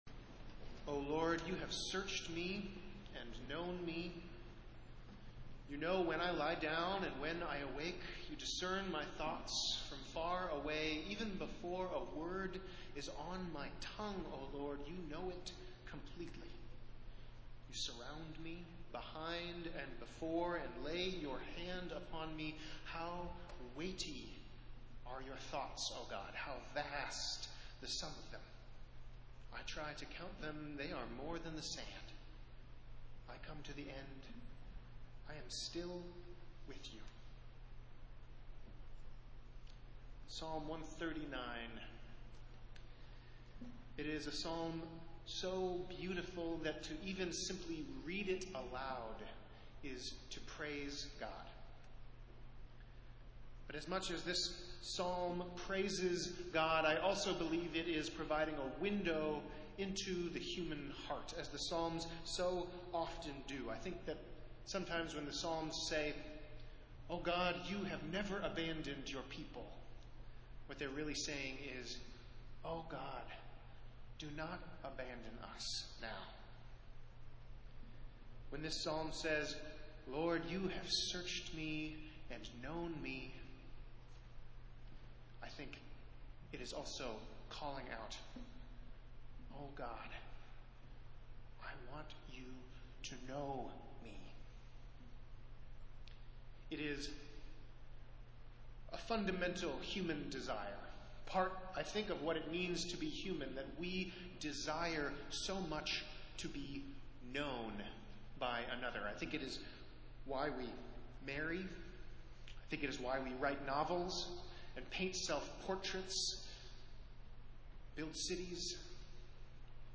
Festival Worship - Fourteenth Sunday after Pentecost